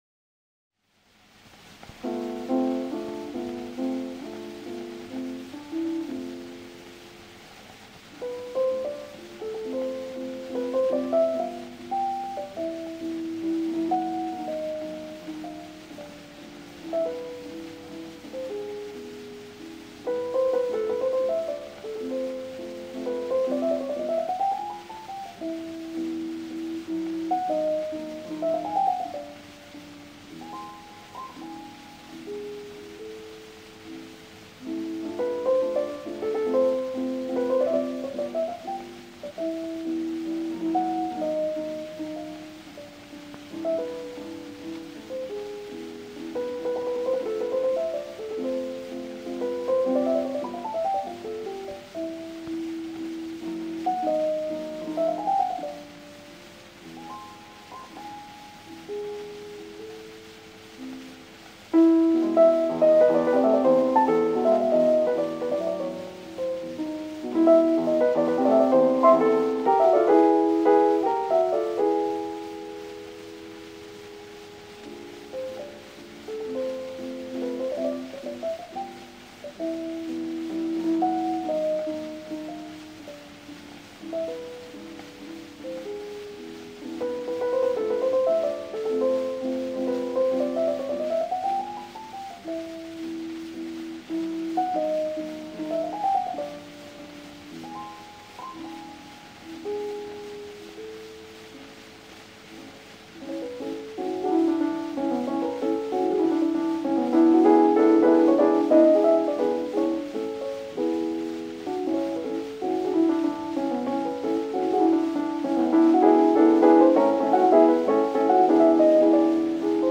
Ignacy Jan Paderewski 1912 – Frédéric Chopin mazurka en la mineur Opus 17 n°4